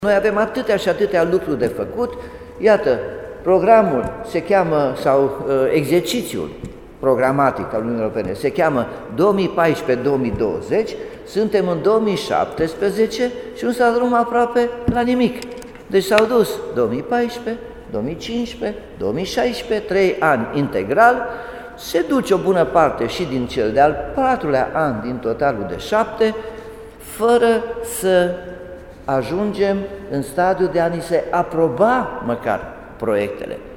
Primarul Nicolae Robu a declarat că se așteaptă ca 2017 să fie un an cu o rată de absorbție foarte scăzută din cauza întârzierii naționale.